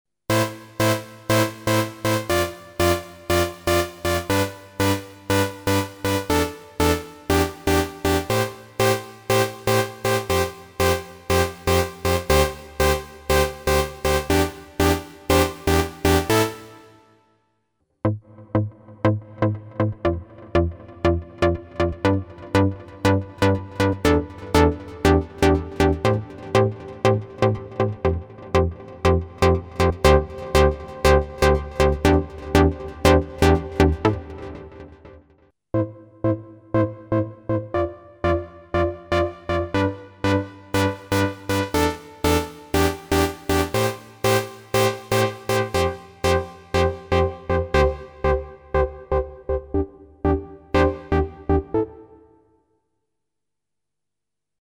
Не VST, правда, но XV-5080, сначала без фильтра, потом с аналоговым фильтром DSI Mopho (на дилей вниманимя не обращать), а потом с родным встроенным цифровым фильтром.